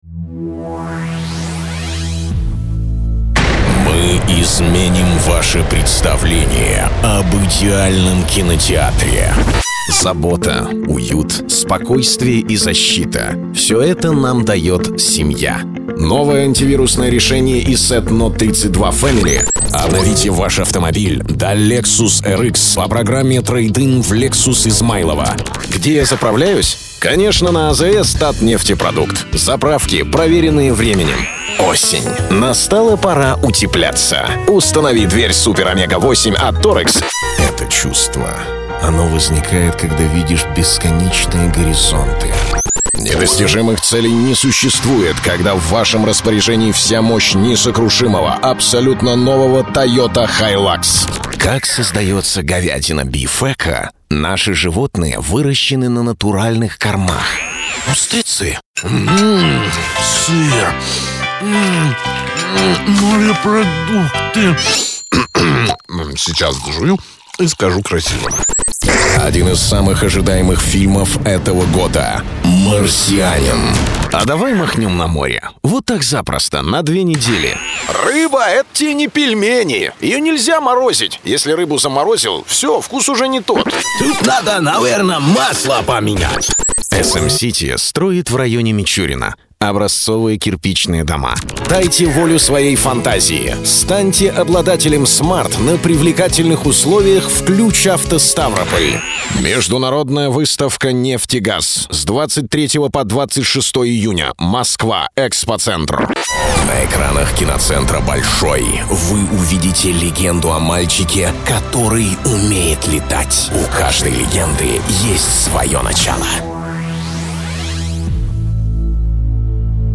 Профессиональный диктор.
Тембр моего голоса - баритон.
Тракт: mics - Rode K2, AKG Perception 220preamp - DBX 376 Tube Channel Stripinterface - Yamaha MW 10cmonitors - Yamaha HS 50mДикторская кабина.